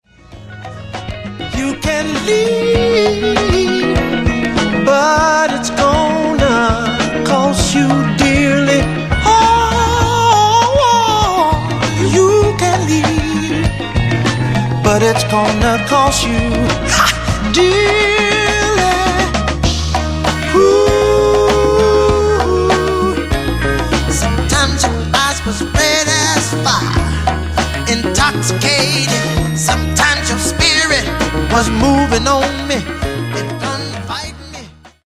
Genere:   Soul | Groove